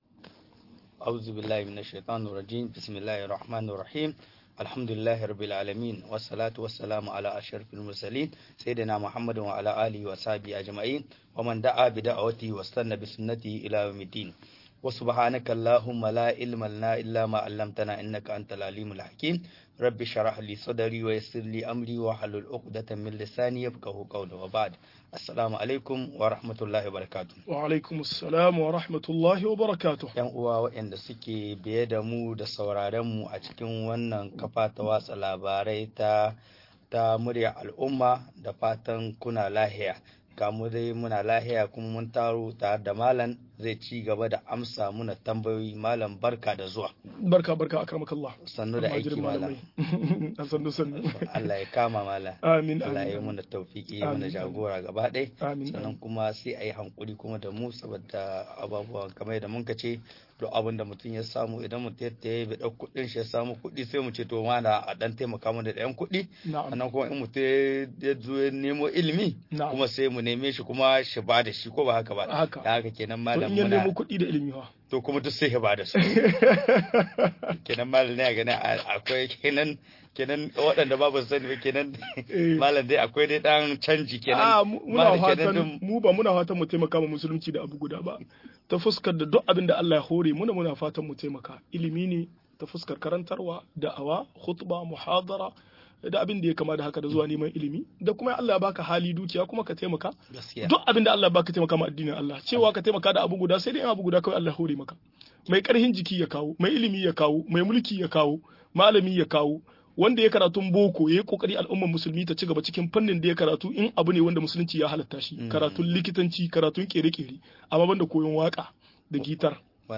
Sunayen Allah da siffofin sa-12 - MUHADARA